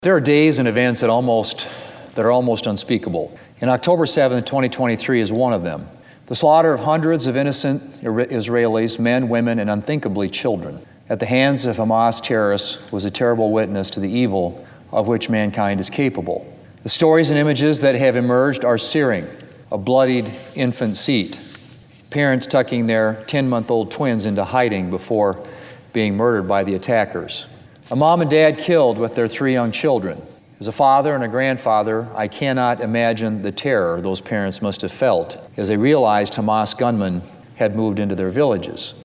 During a recent speech (Oct. 17, 2023) on the floor of the United States Senate, South Dakota Senator John Thune condemned the attacks on Israel by Hamas terrorists.